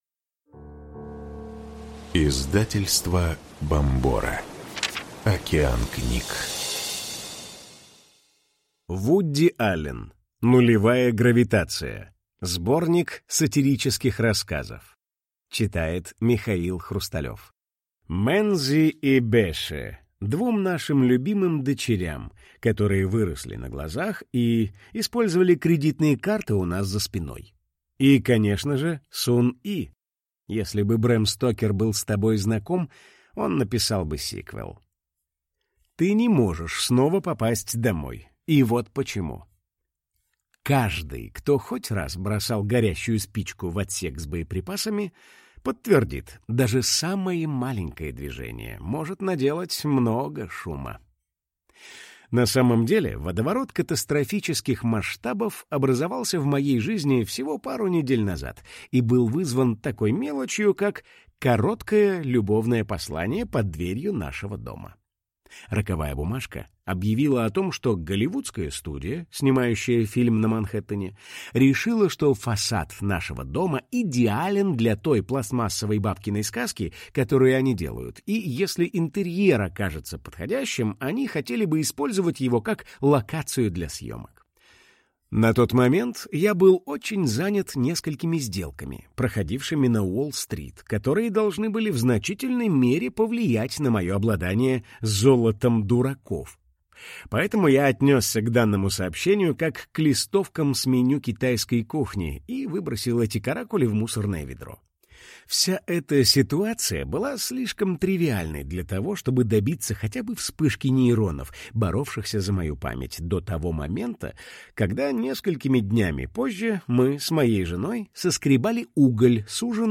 Аудиокнига Нулевая гравитация. Сборник сатирических рассказов Вуди Аллена | Библиотека аудиокниг